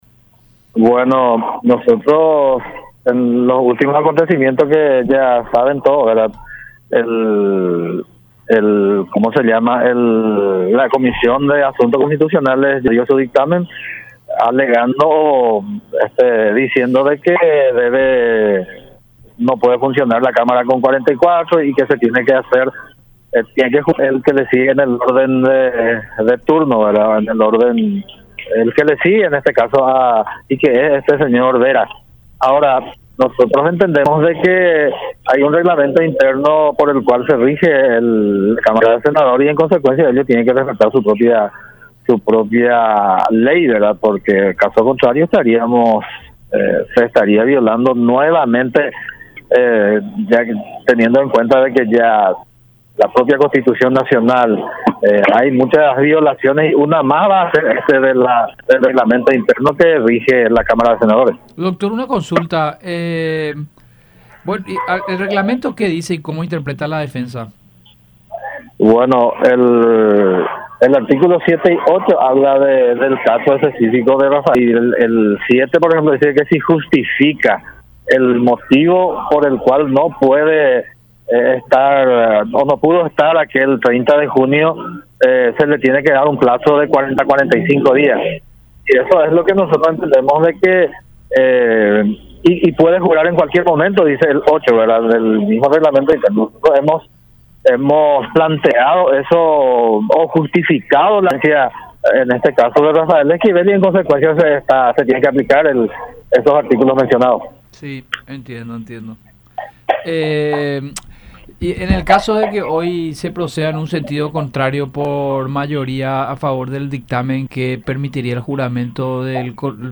en conversación con el programa “La Unión Hace La Fuerza” por Unión TV y radio La Unión.